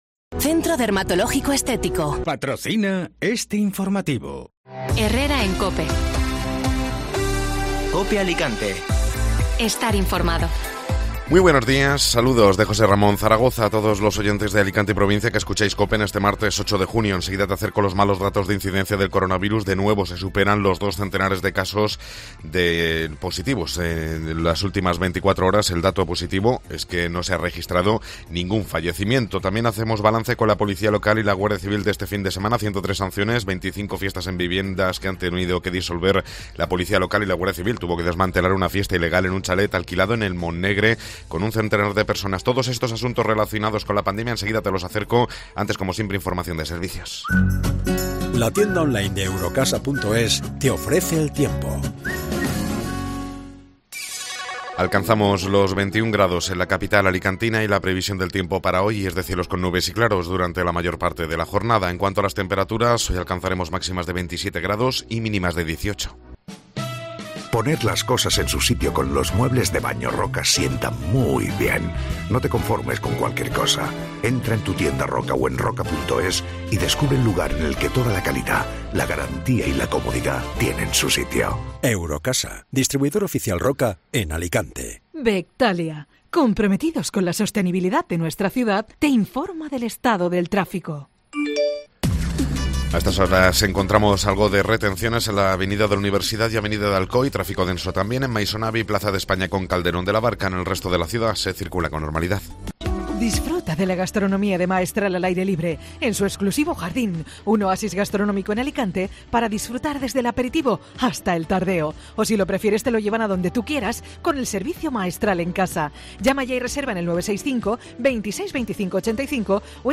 Informativo Matinal (Martes 8 de Junio)